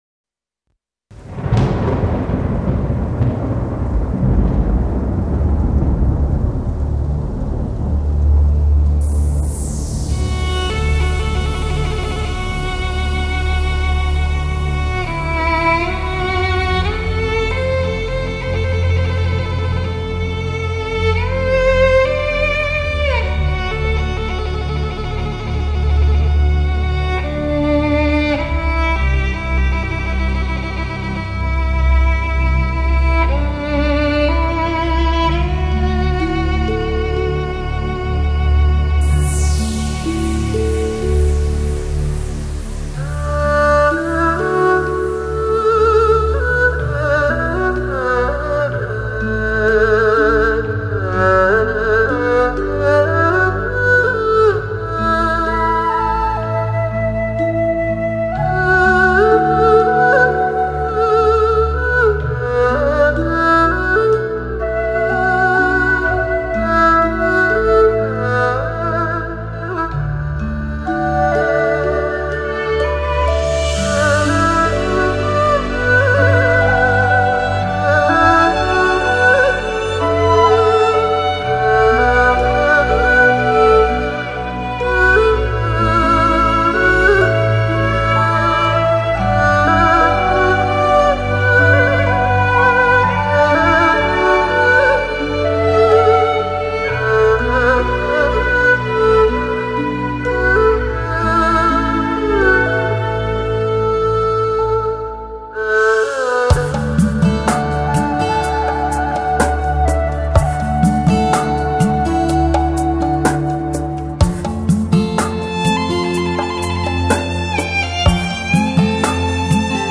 中胡，是在二胡基础上改制的一种乐器，是“中音二胡”的简称。
中胡是民乐队中重要的中音乐器，音色浑厚，最善于演奏一些抒展、辽阔的歌唱性旋律。